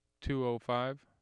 4. Spectrogram and AIF tracks for speech utterance “